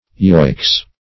yoicks - definition of yoicks - synonyms, pronunciation, spelling from Free Dictionary Search Result for " yoicks" : The Collaborative International Dictionary of English v.0.48: Yoicks \Yo"icks\, interj. (Hunting) A cry of encouragement to foxhounds.